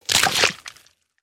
zvuk-kotoryy-budet-esli-votknut-v-skeleta-ruku-26
• Качество: Высокое